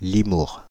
Limours, often referred to as Limours-en-Hurepoix (French pronunciation: [limuʁ]